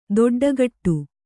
♪ doḍḍagaṭṭu